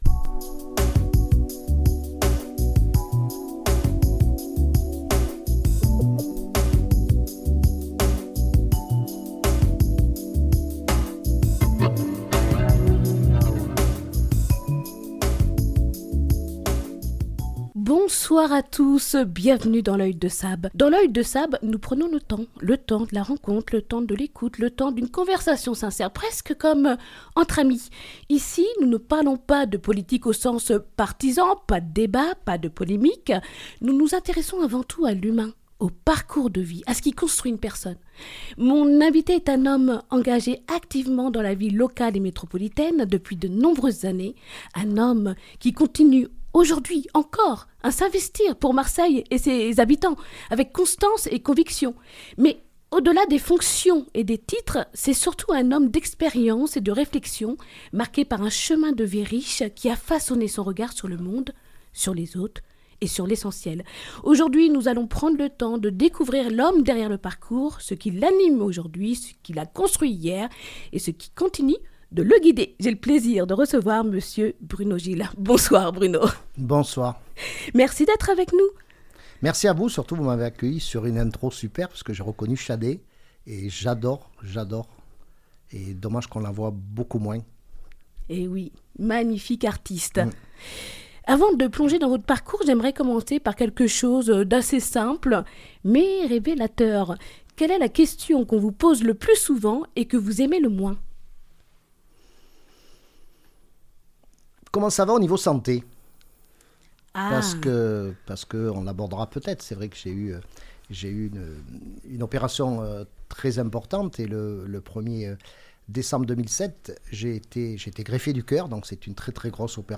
Une conversation humaine, sincère et authentique.